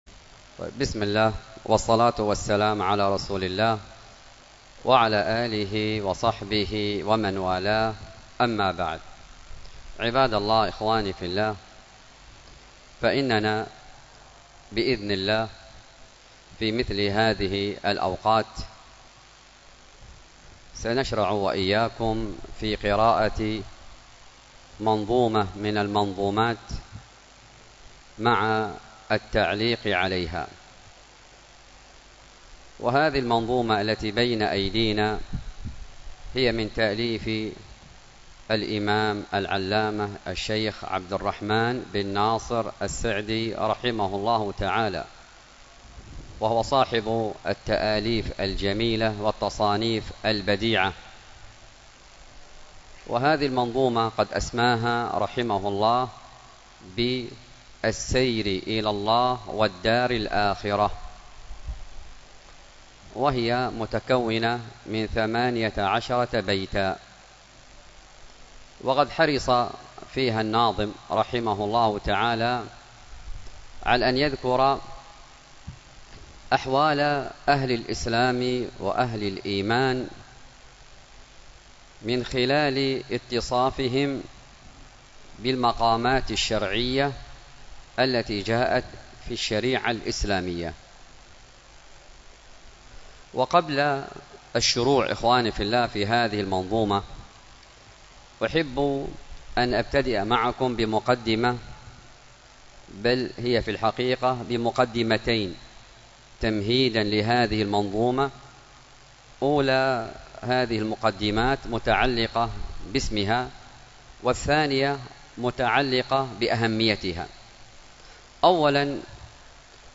الدرس في شرح السياسة الشرعية 27، الدرس السابع والعشرون:من( فإن الناس ثلاثة أقسام:قسم يغضبون لنفوسهم ولربهم ... بل اشترط بعضهم المطالبة بالمال لئلا يكون للسارق فيه شبهة ).